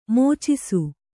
♪ mōcisu